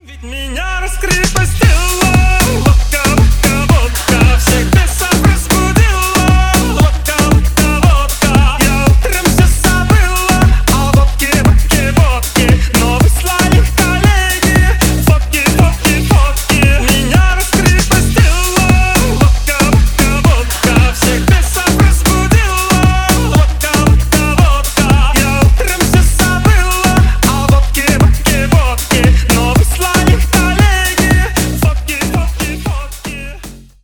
• Качество: 320 kbps, Stereo
Танцевальные
весёлые